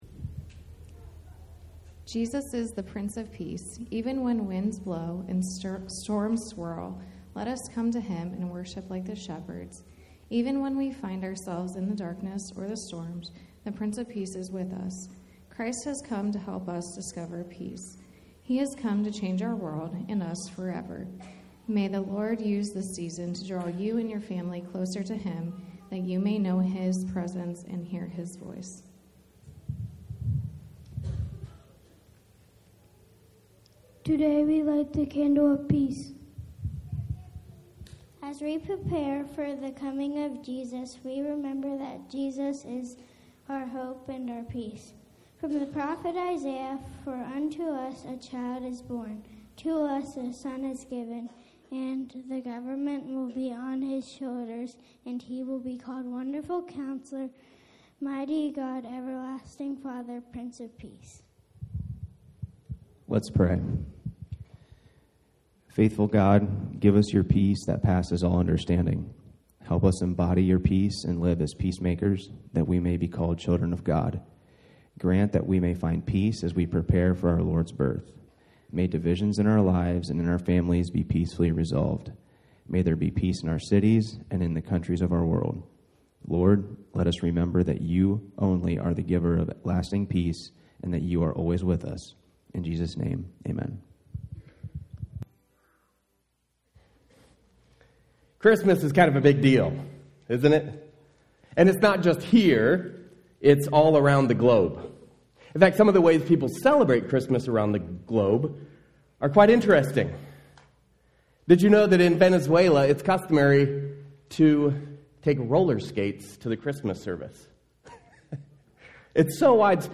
Sermons | Mennonite Christian Assembly